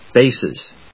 音節bas・es 発音記号・読み方
/béɪsiːz(米国英語), ˈbeɪsʌz(英国英語)/